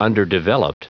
Prononciation du mot underdeveloped en anglais (fichier audio)
Prononciation du mot : underdeveloped
underdeveloped.wav